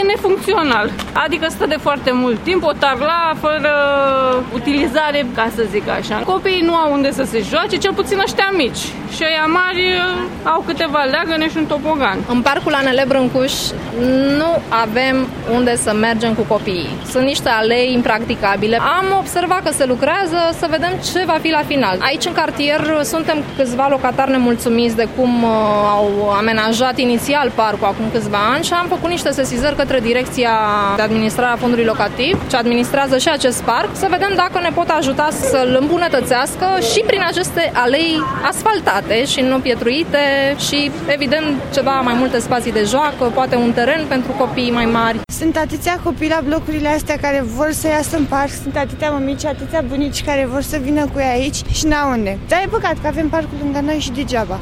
In zona lipsesc spatiile de joaca pentru copii, dar si aleile si curatenia spun mamicile care se vad nevoite sa caute alternative de petrecere a timpului liber.
vox-parc-anl-5-iun.mp3